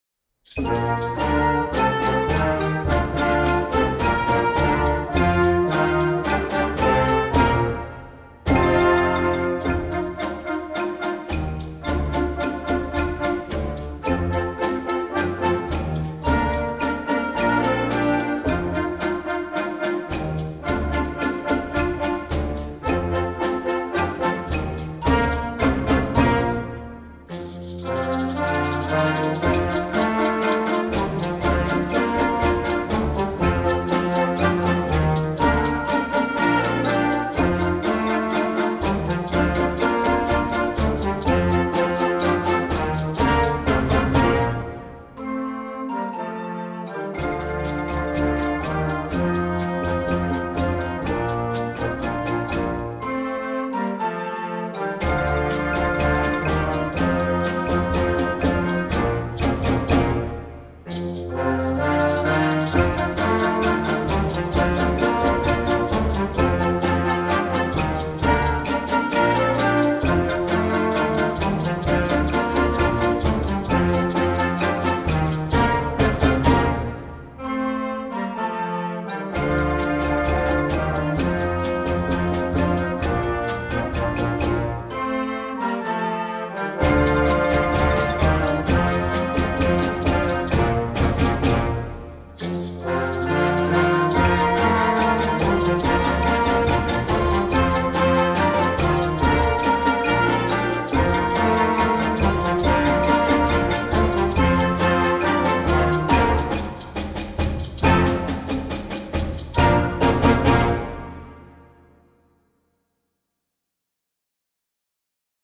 Instrumental Concert Band Light Concert/Novelty
a fun and energetic piece
reimagined in a lively Latin style
Concert Band